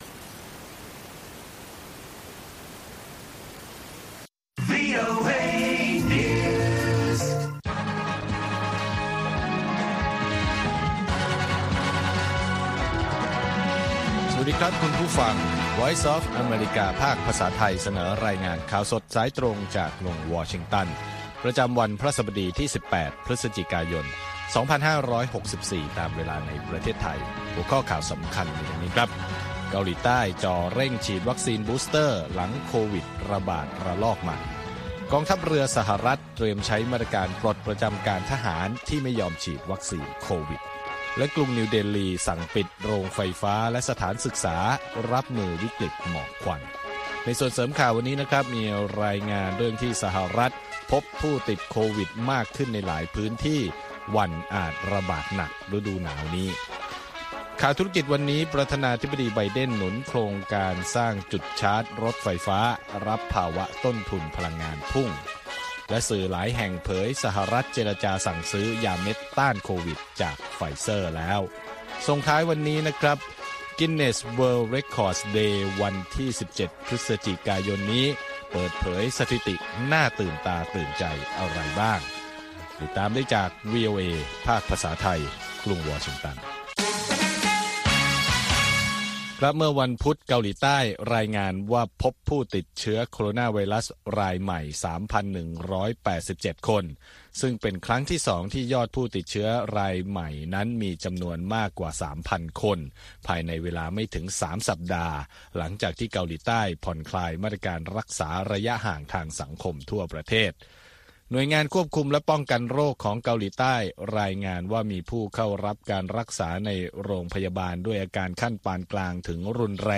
ข่าวสดสายตรงจากวีโอเอ ภาคภาษาไทย 8:30–9:00 น. ประจำวันพฤหัสบดีที่ 18 พฤศจิกายน 2564 ตามเวลาในประเทศไทย